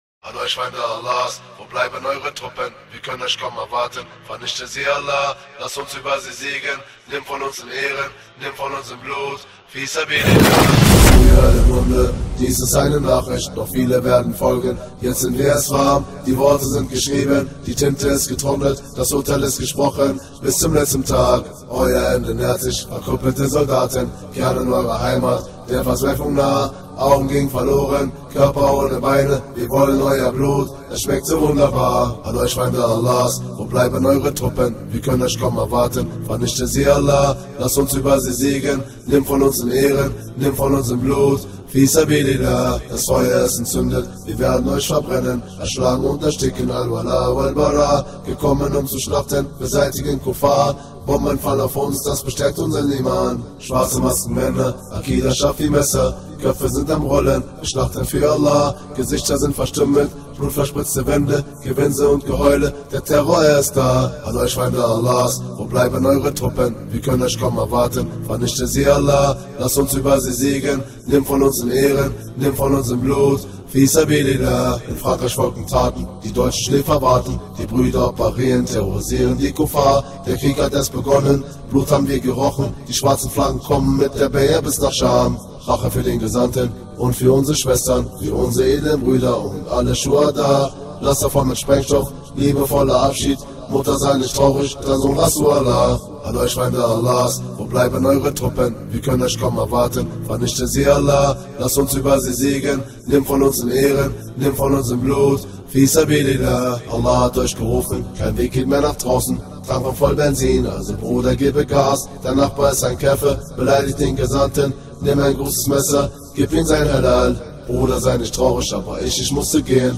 Fisabilillah (German Nashe….mp3 📥 (4.79 MB)